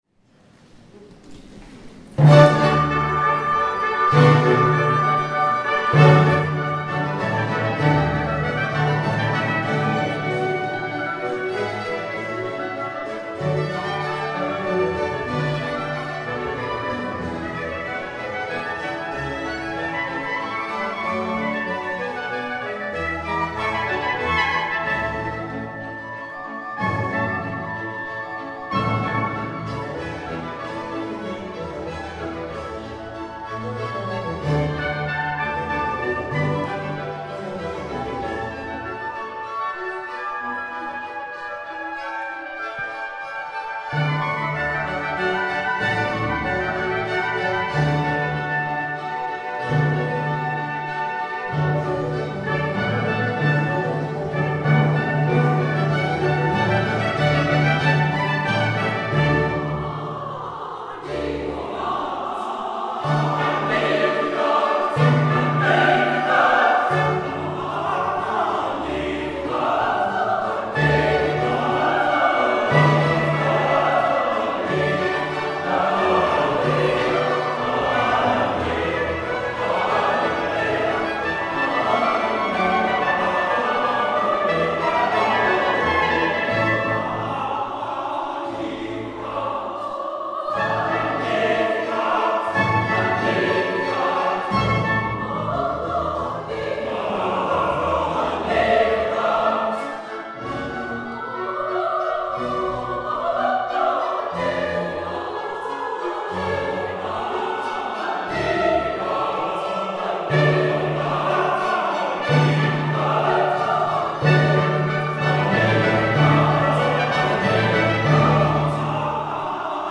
Concerts du Bach Ensemble Amsterdam :
Beaucoup de villageois, des habitants des environs et des d�partements voisins et des repr�sentants de la vie associative et politique du d�partement et de la r�gion sont venus �couter L�Ensemble.
BWV214 de Bach , puis a continu� avec le Grand motet, In Exitu Israel de De Mondonville puis fini avec Bach et son Magnificat, BWV243 .